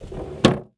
household
Desk Drawer Closed